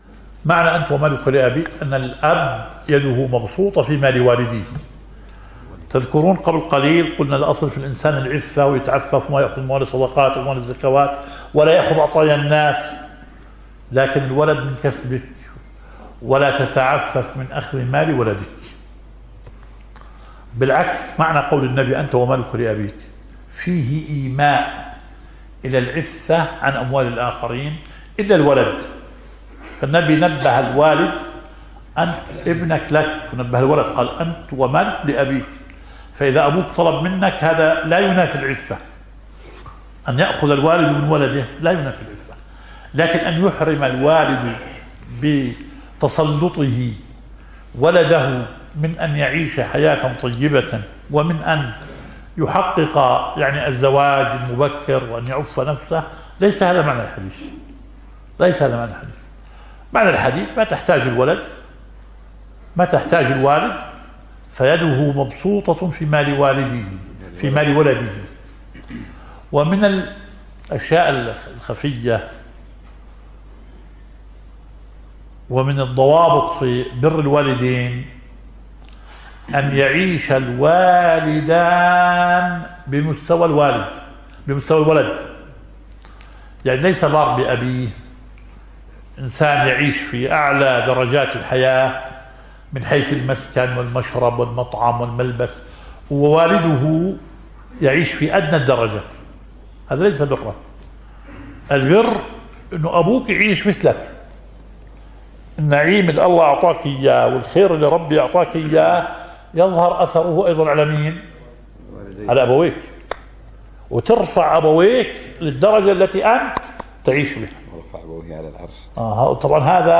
⬅ مجلس فتاوى الجمعة.